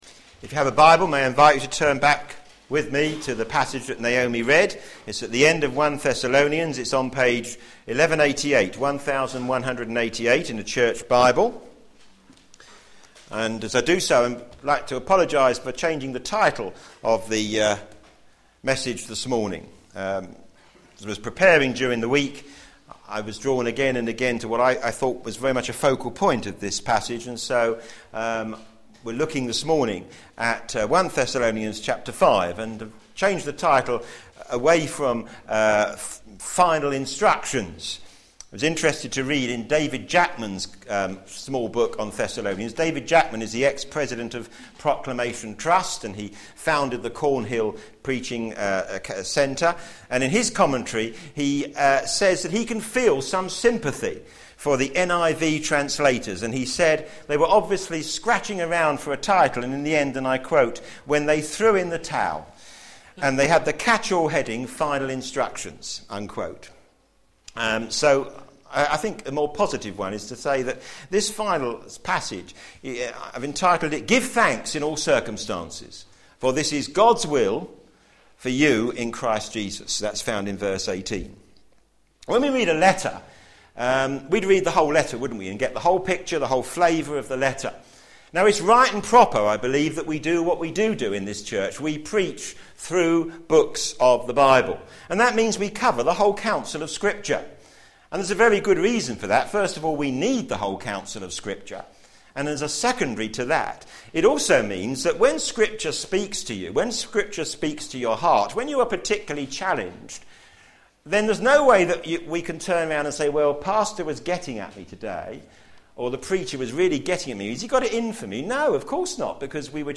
a.m. Service
The Practical Guidance of the Church Sermon